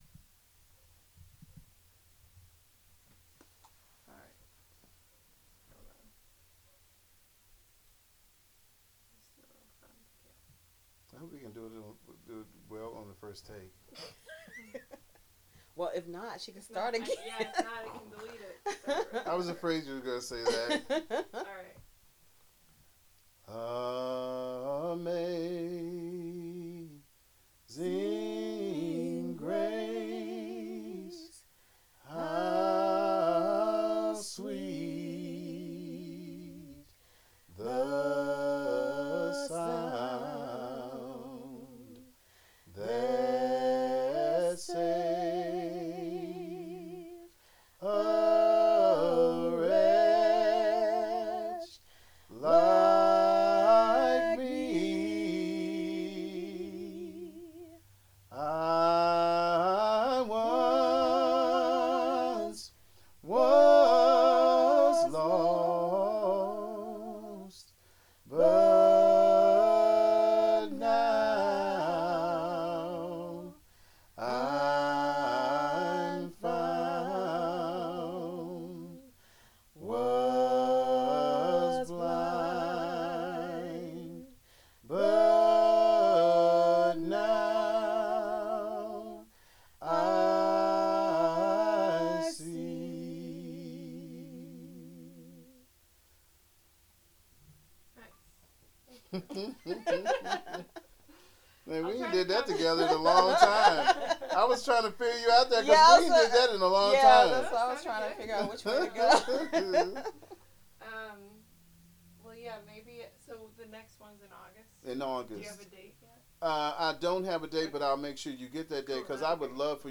Rhythm and blues music, Apprenticeship programs, Gospel music, African Americans--Appalachian Region, Soul music